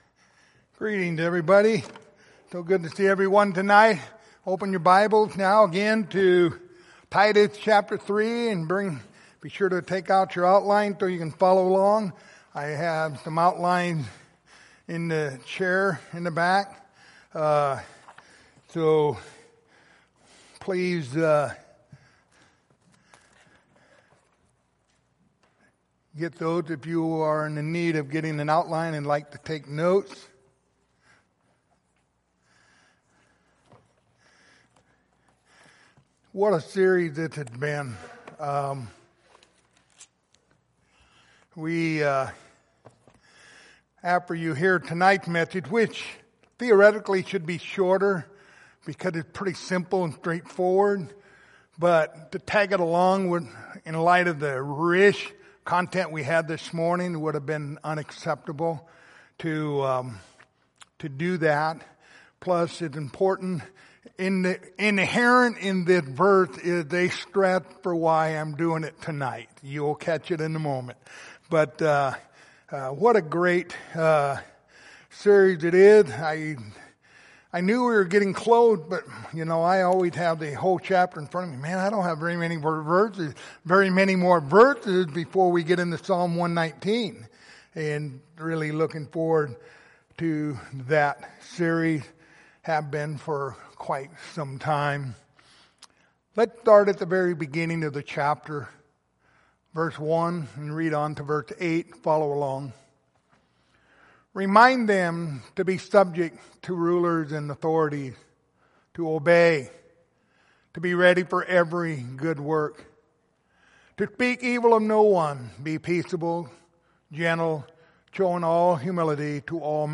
Passage: Titus 3:8 Service Type: Sunday Evening Topics